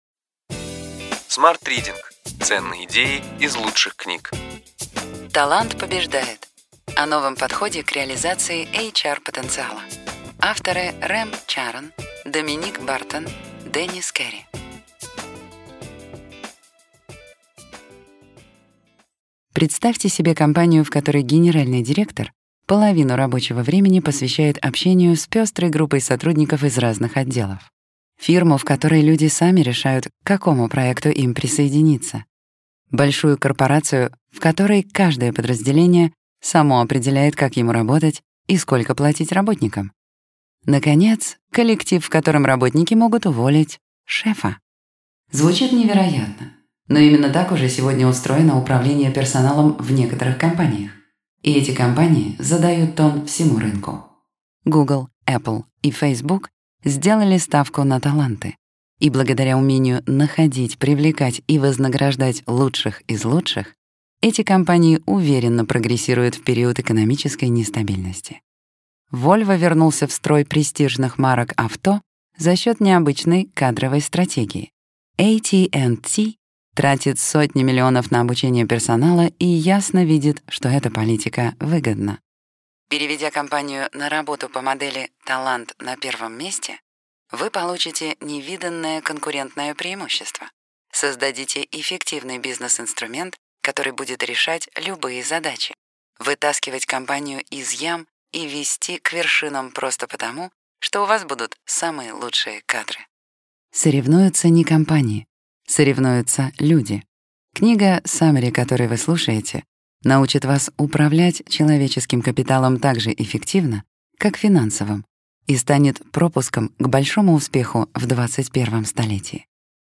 Аудиокнига Ключевые идеи книги: Талант побеждает. О новом подходе в реализации НR-потенциала.